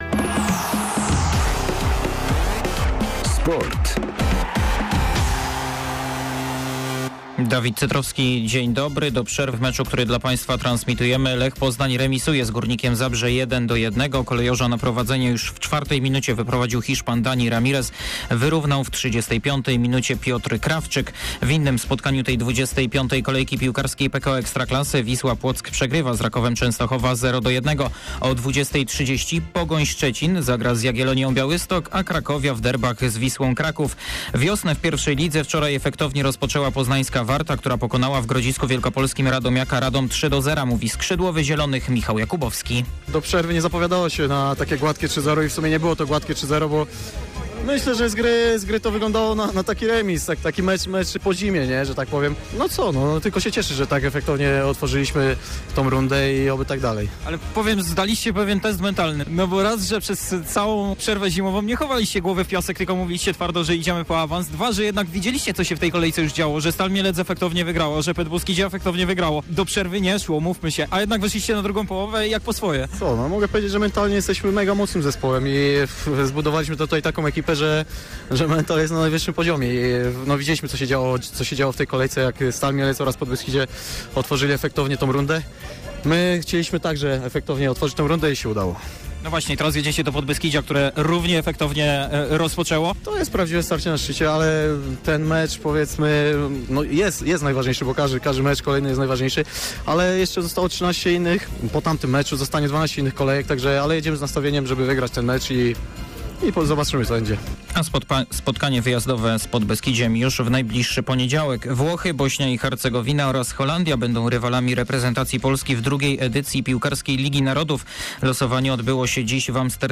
03.03. SERWIS SPORTOWY GODZ. 19:05